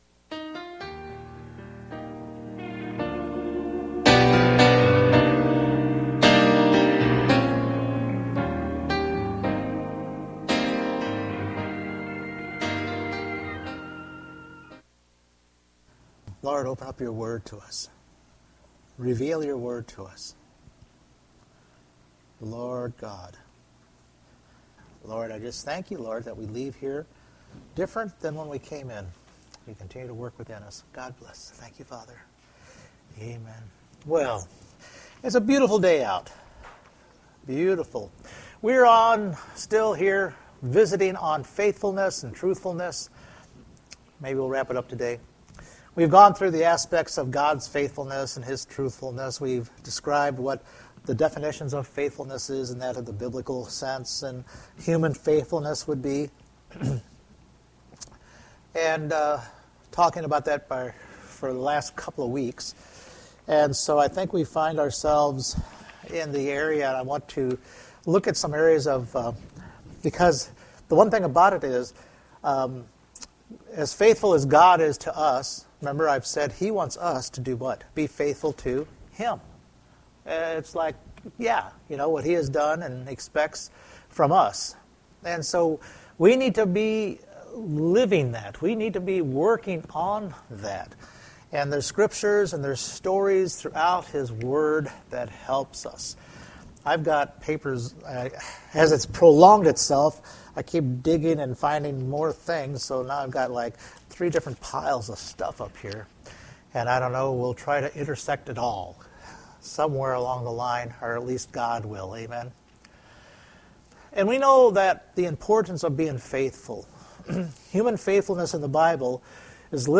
Several Service Type: Sunday Morning As God is faithful we too need to be likewise to Him.